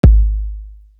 Essence Kick.wav